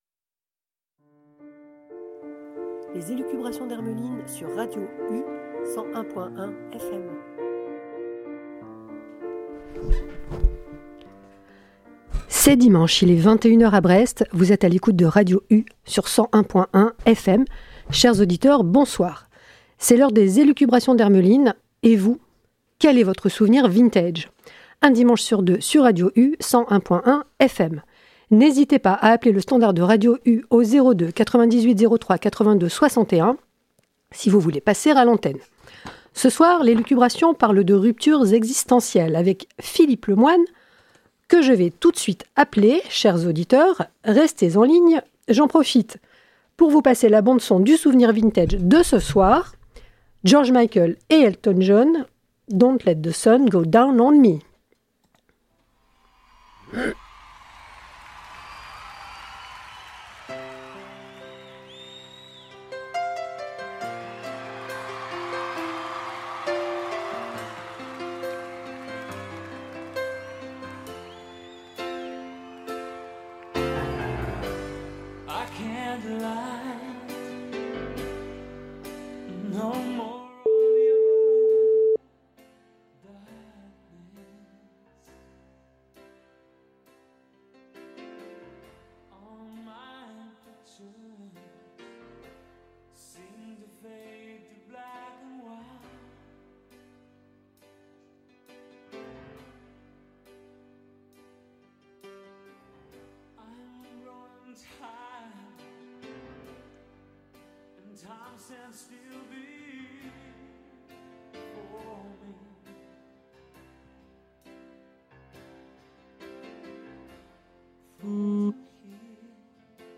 Jingle intro & outro